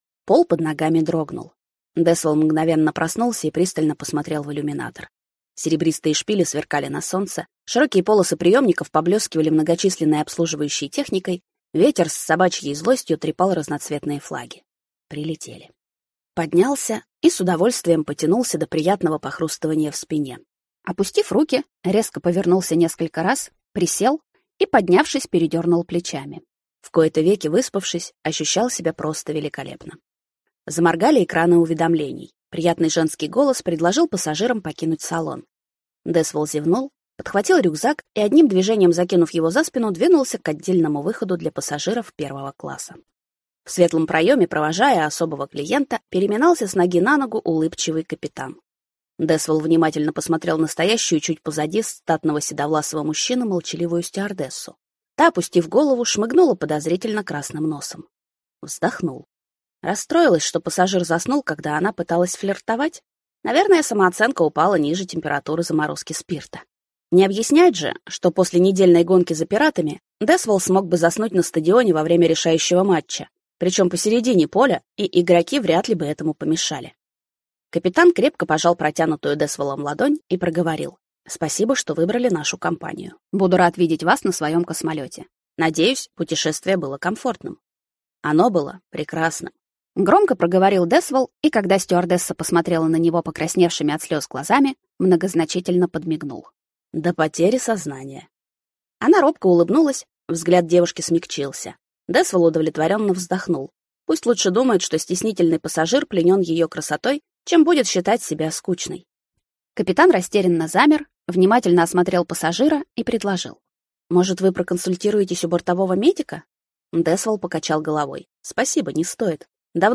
Аудиокнига Все пути ведут в Рай | Библиотека аудиокниг